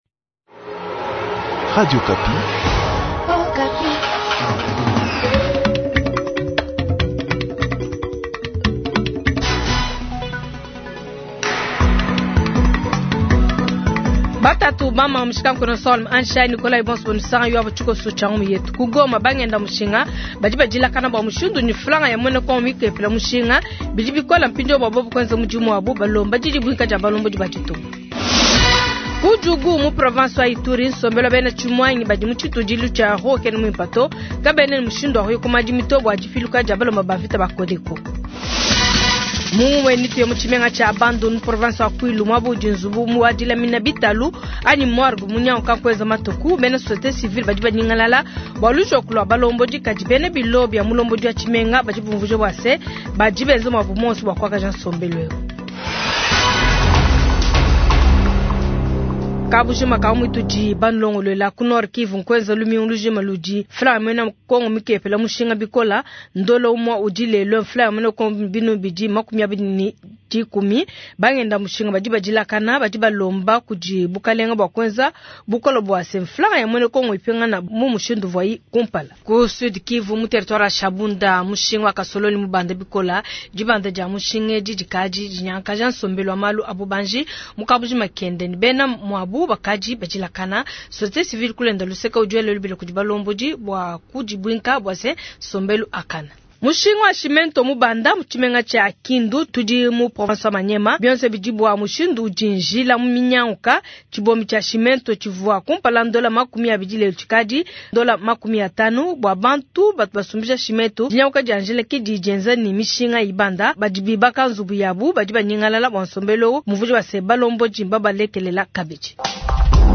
Jounal soir